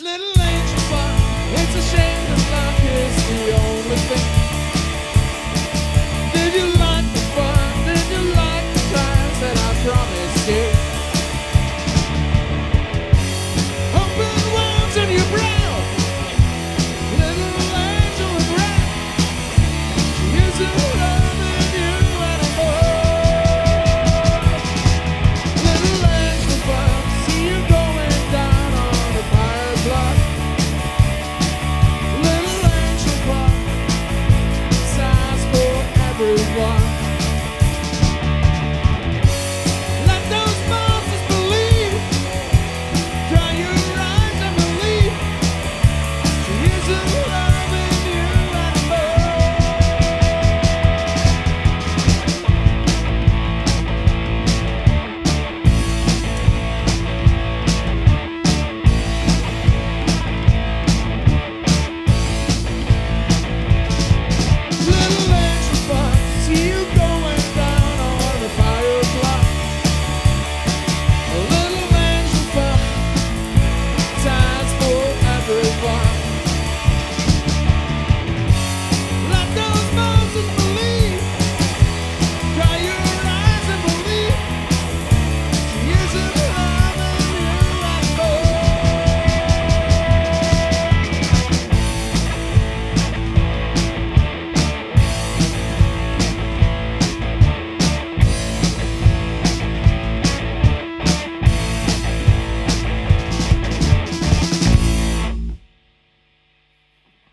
Punkrock